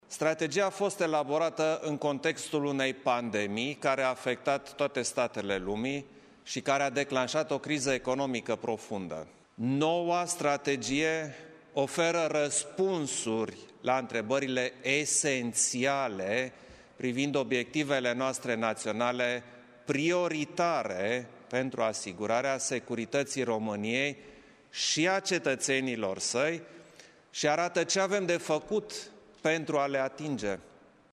Declarații ale președintelui Klaus Iohannis la finalul ședintei CSAT
Pe lângă apărarea armată, strategia vizează și politica externă, ordinea publică, sănătatea, economia, mediul sau securitatea cibernetică, a declarat președintele Iohannis: